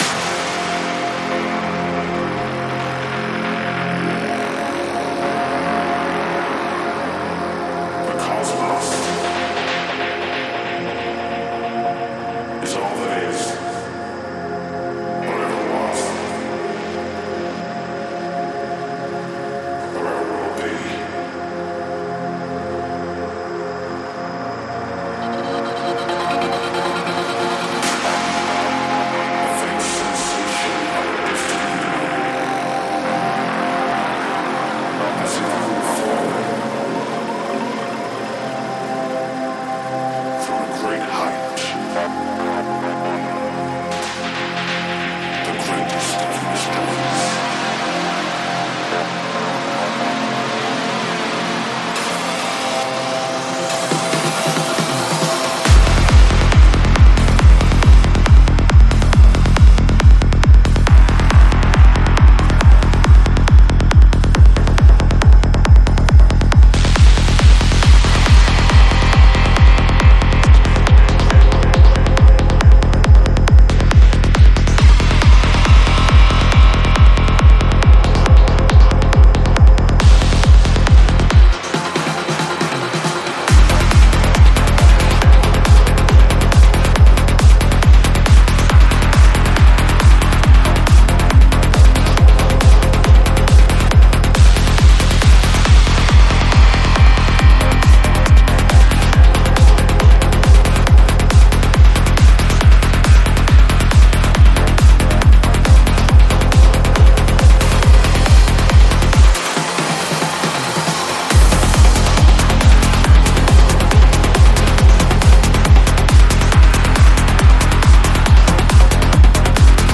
Альбом: Psy-Trance